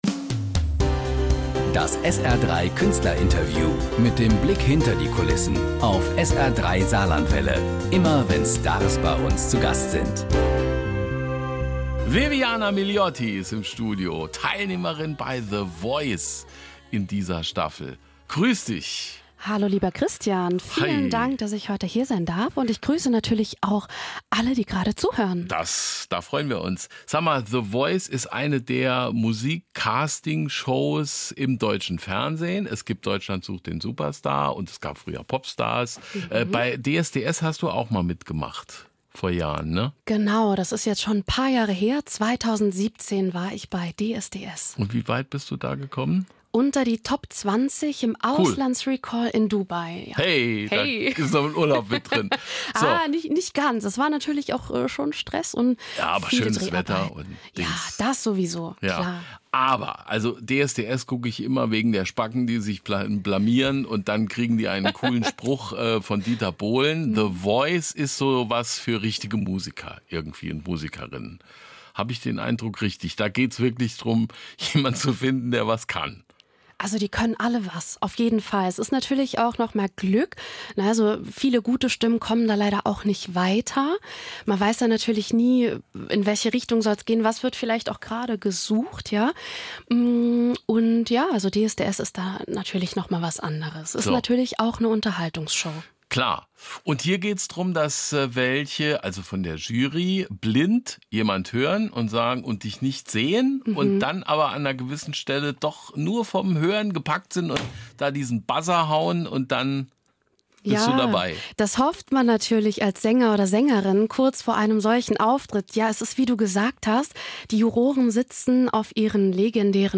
Künstlerinterviews
Auf SR 3 Saarlandwelle - immer wenn Stars bei SR 3 zu Gast sind.